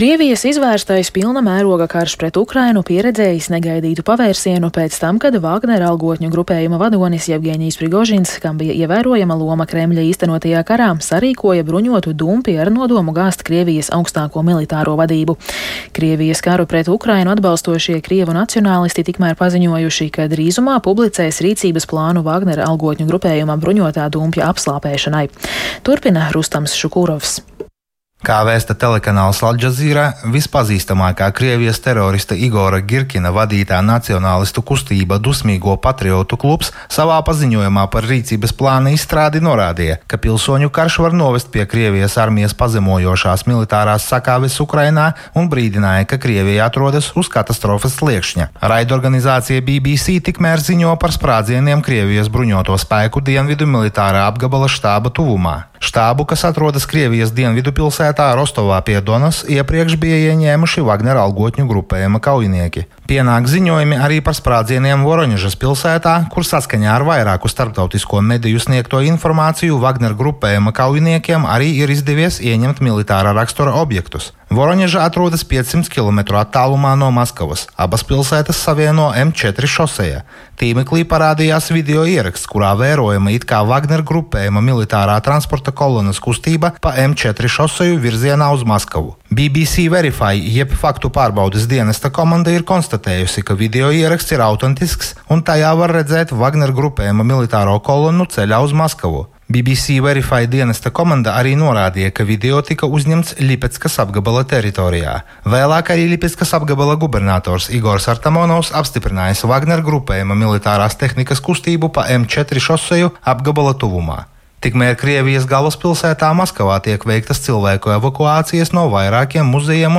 Krievijas algotņu grupējuma "Vagner" vadītāja Jevgeņija Prigožina mēģinājums gāzt Krievijas militāro vadību izskatās pēc klasiska militārā dumpja, intervijā Latvijas Radio sacīja ārlietu ministrs Edgars Rinkēvičs ("Jaunā Vienotība").